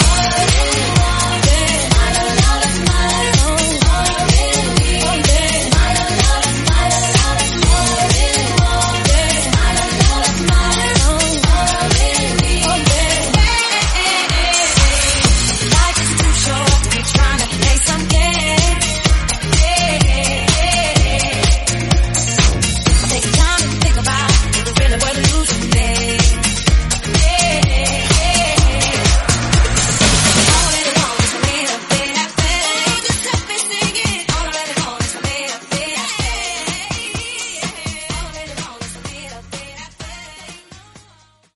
BOOTLEG , EDM , MASHUPS